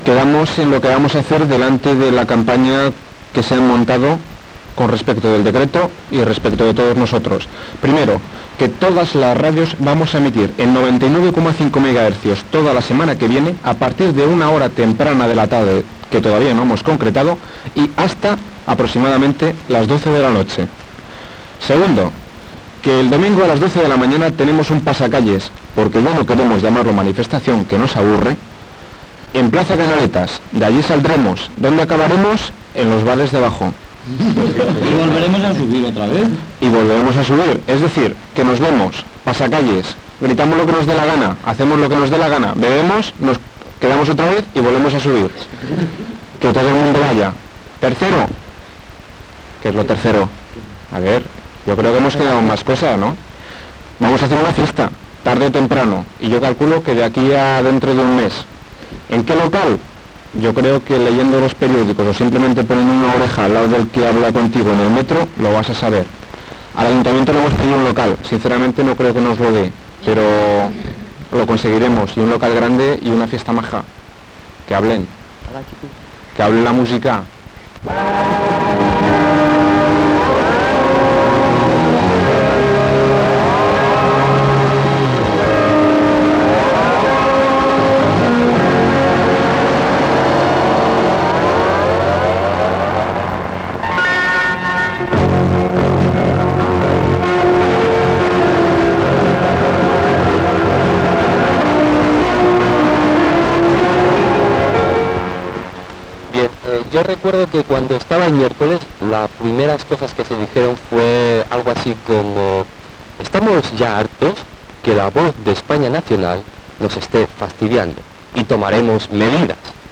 Informatiu
Emissió feta des de La Campana de Gràcia, dos dies després del quart tancament de l'emissora i precintat de la seva seu i equips.